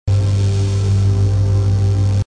equipment_cart_loop.wav